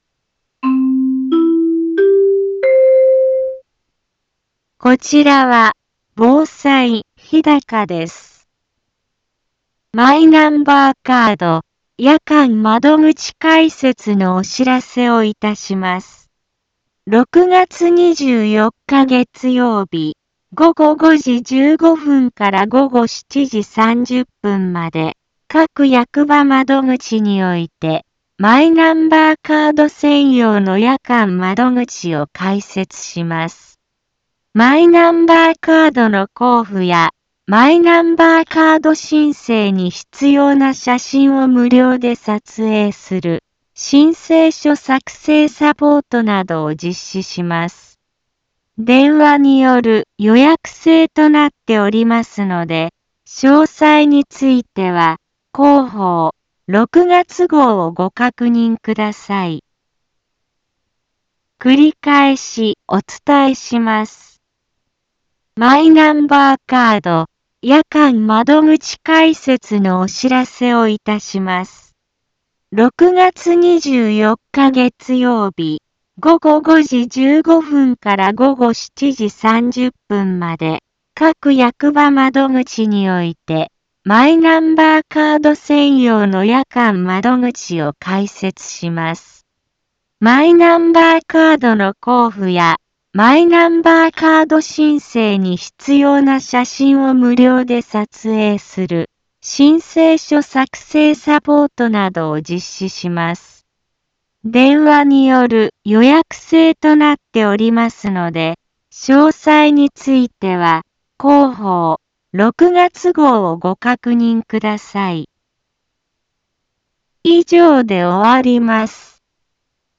Back Home 一般放送情報 音声放送 再生 一般放送情報 登録日時：2024-06-17 15:04:32 タイトル：マイナンバーカード夜間窓口開設のお知らせ インフォメーション： マイナンバーカード夜間窓口開設のお知らせをいたします。 6月24日月曜日、午後5時15分から午後7時30分まで、各役場窓口において、マイナンバーカード専用の夜間窓口を開設します。